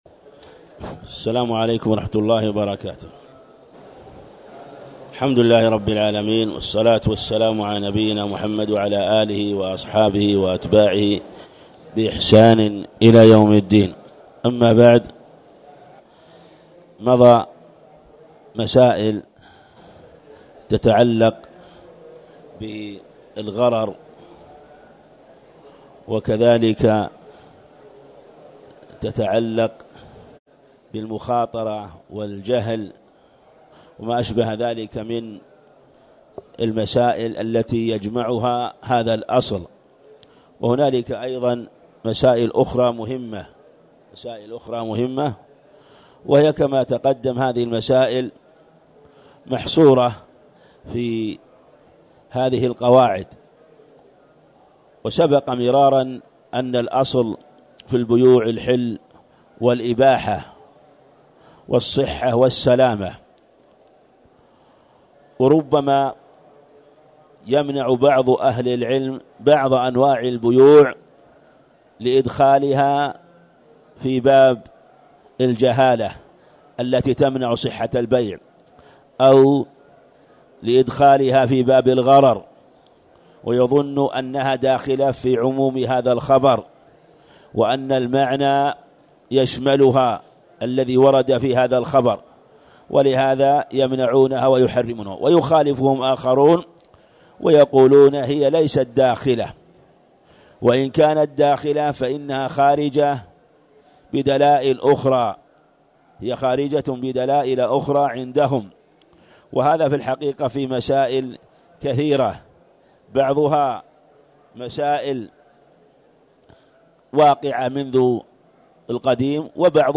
تاريخ النشر ١٣ رمضان ١٤٣٨ هـ المكان: المسجد الحرام الشيخ